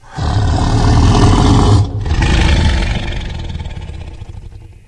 giant_idle_0.ogg